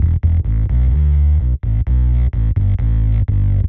Index of /musicradar/dub-designer-samples/130bpm/Bass
DD_PBassFX_130E.wav